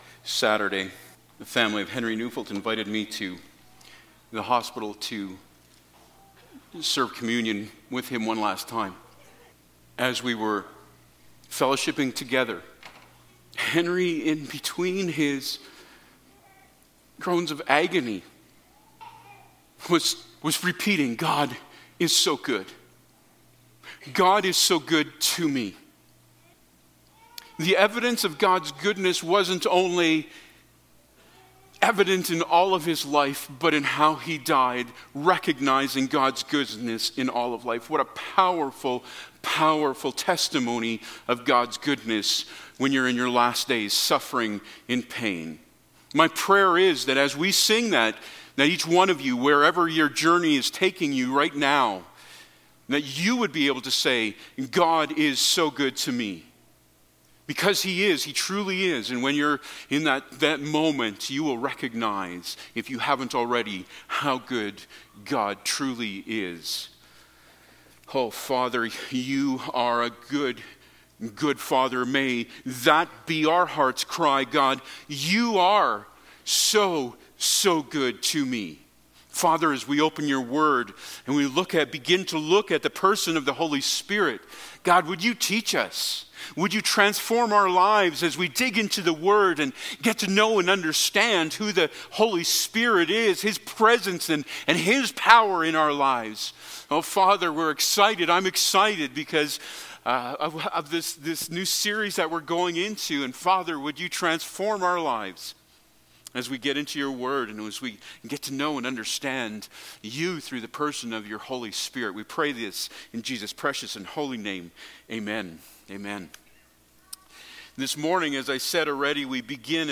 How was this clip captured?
Service Type: Sunday Morning Topics: Holy Spirit